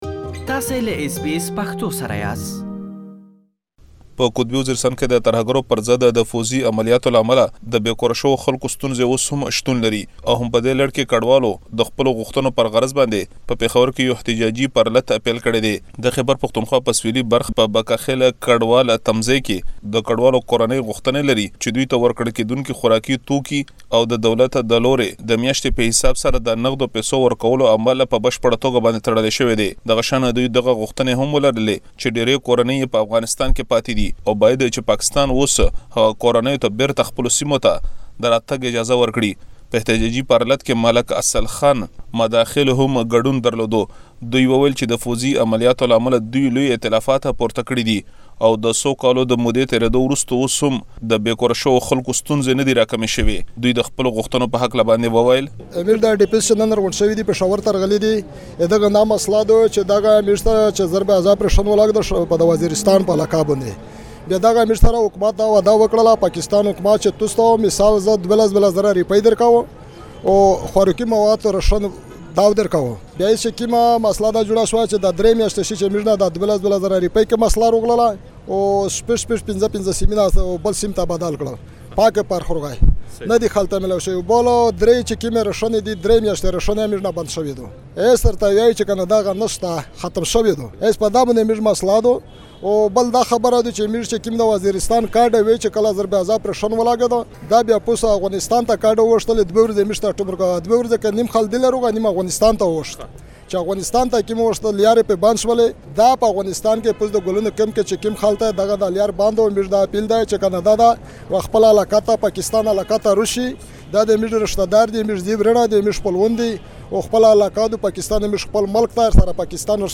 زمونږ همکار له يادو مظاهره کوونکو سره خبرې کړي او غږونه يې راخيستي چې تاسې دا ټول بشپړ رپوت کې اوريدلی شئ.